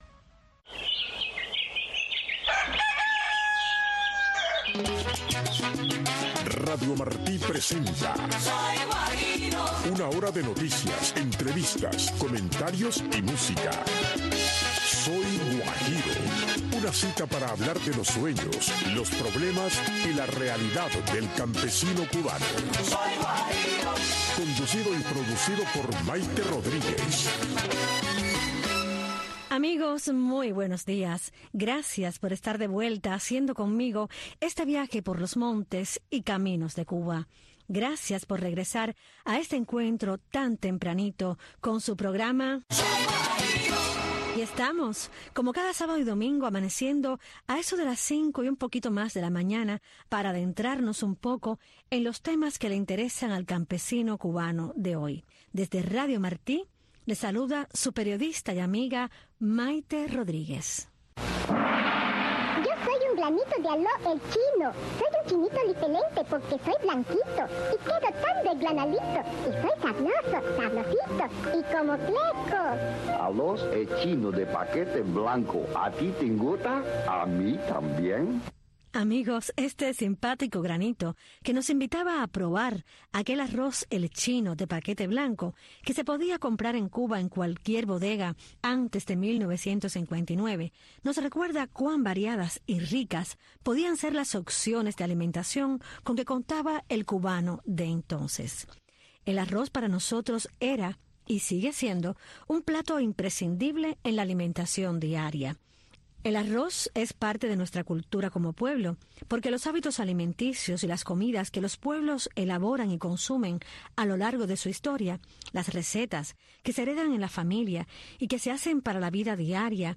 Soy Guajiro es un programa para los campesinos y guajiros con entrevistas, música y mucho más.